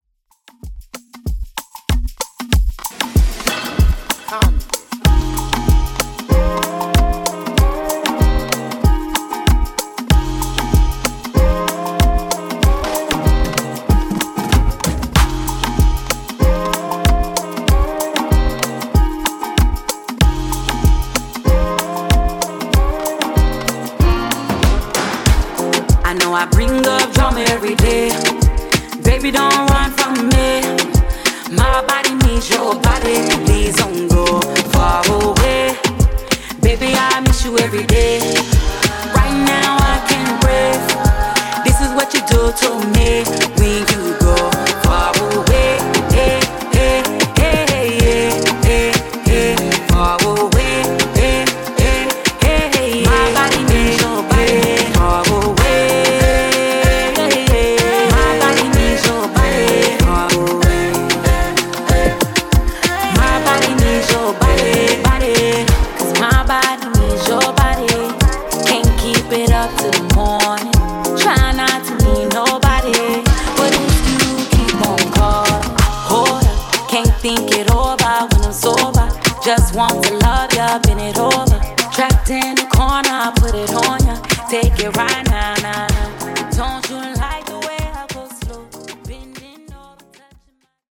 Afrobeat)Date Added